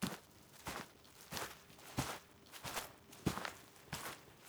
SFX_Footsteps_Path_Forest_01.wav